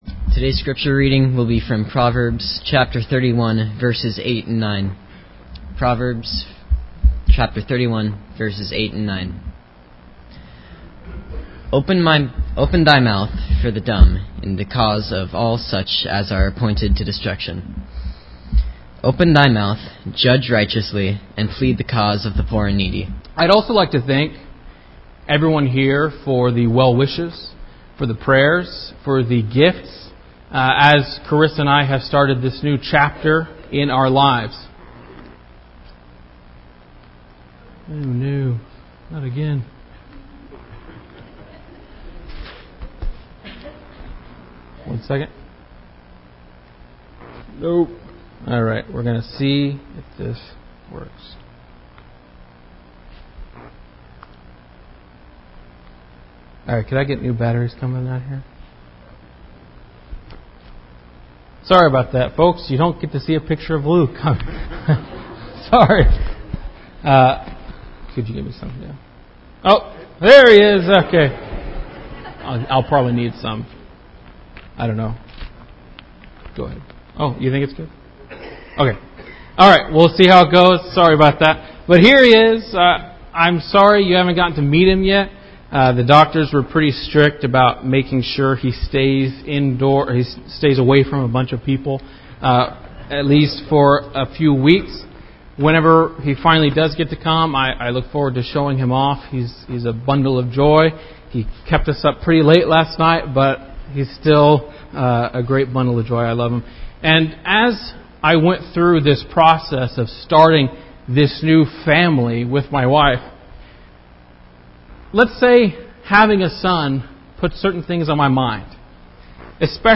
The scripture for this lesson is Proverbs 31:8-9